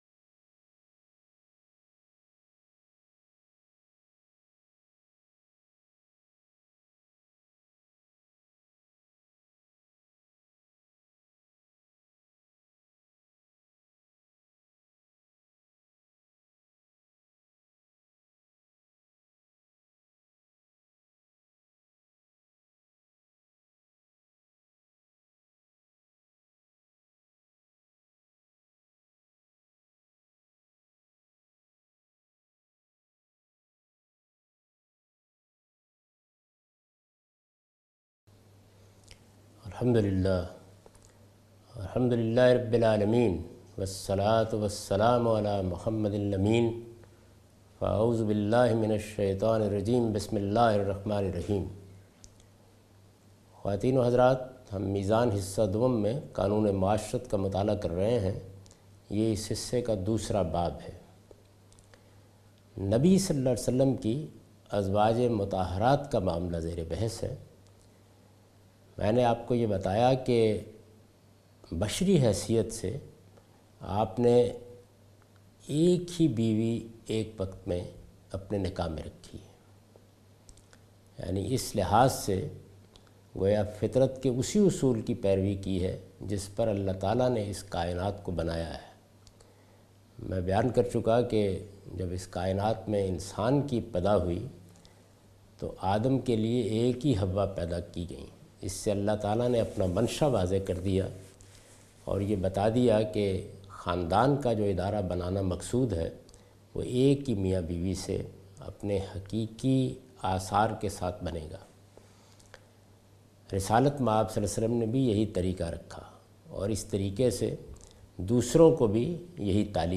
A comprehensive course on Islam, wherein Javed Ahmad Ghamidi teaches his book ‘Meezan’.